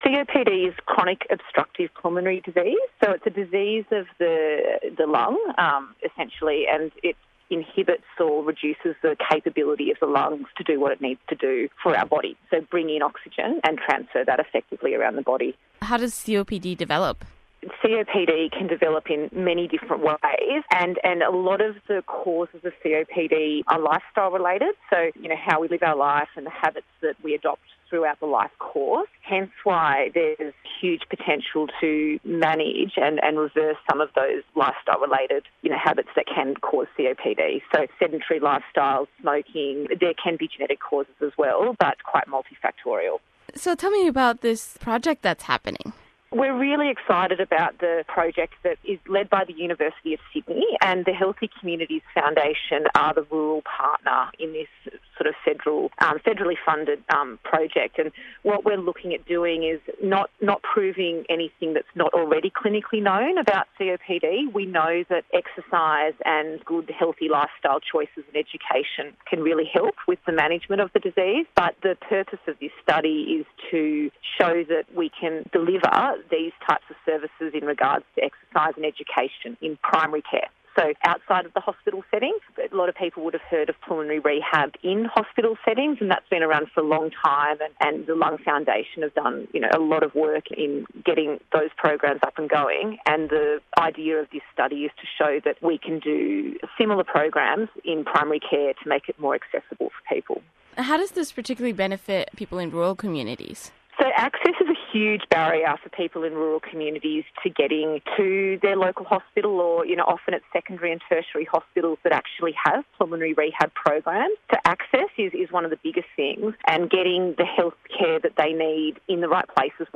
discusses innovative COPD trial with ABC